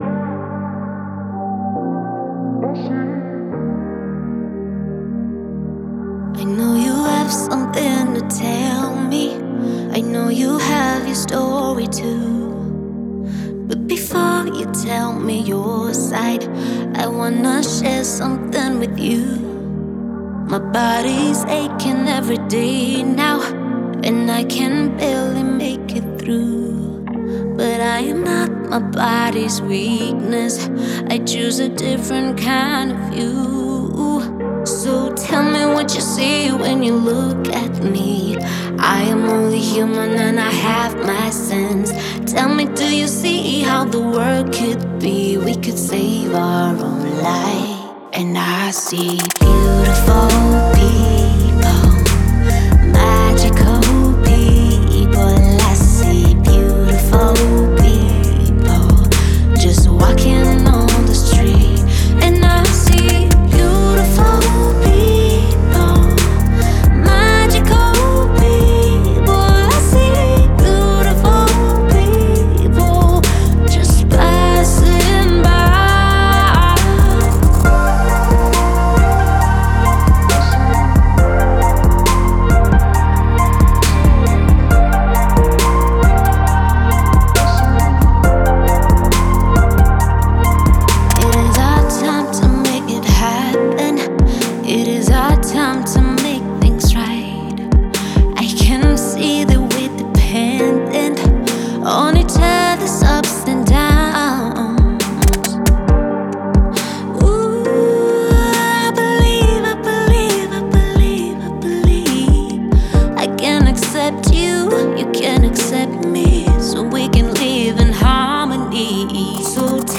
романтическая поп-песня